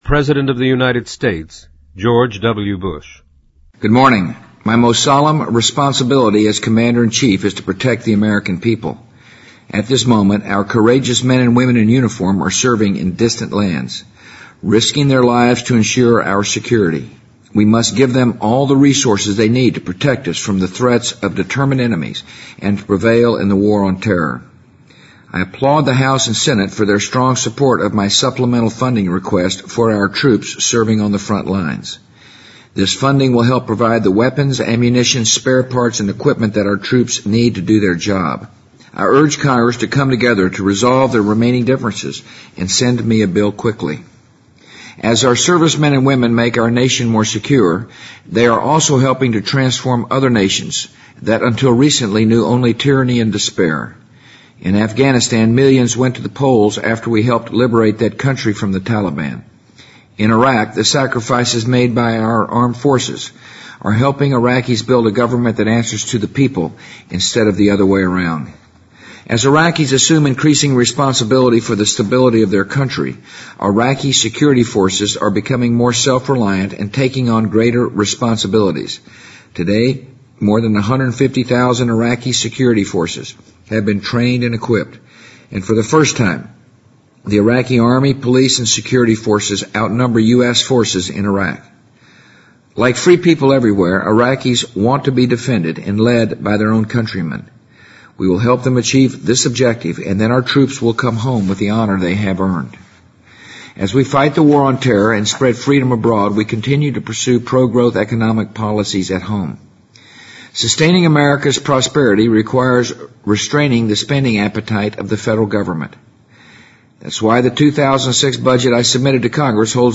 在线英语听力室President Bush-2005-04-23电台演说的听力文件下载,美国总统电台演说-在线英语听力室